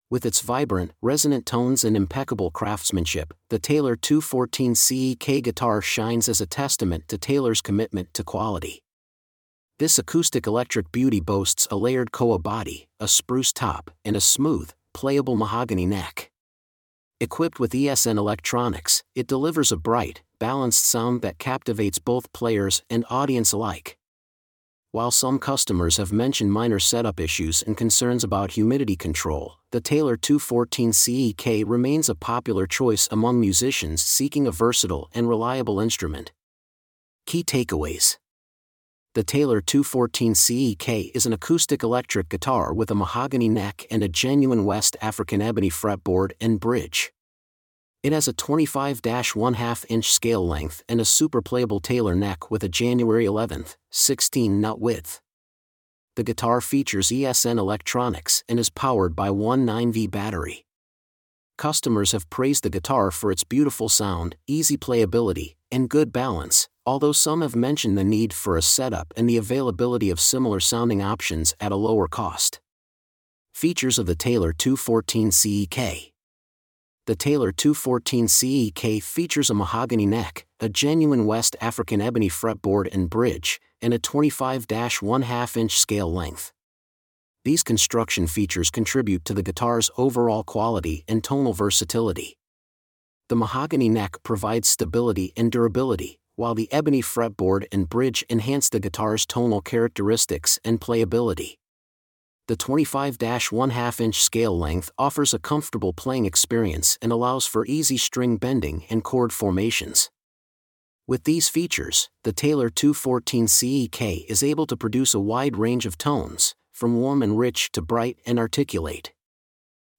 Taylor 214CE-K Review.mp3